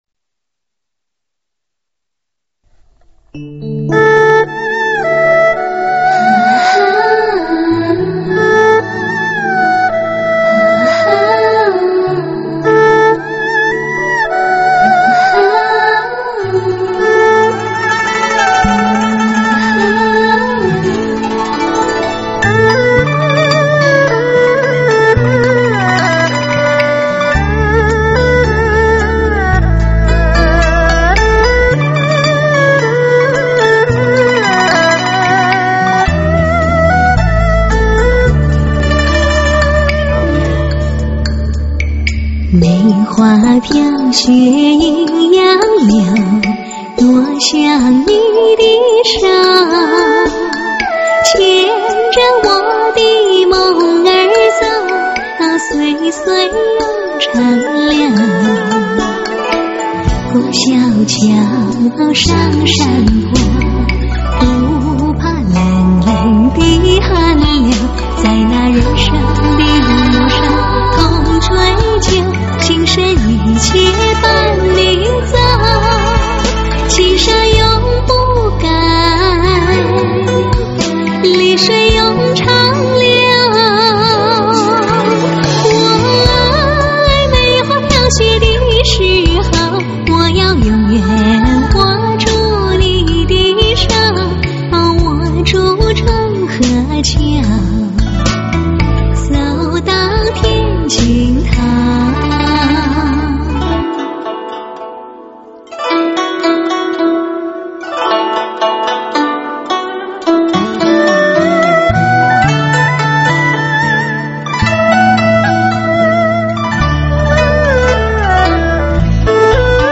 吹所若兰，飘然迷离，听着她们的歌声，真是一种全新超然的享受。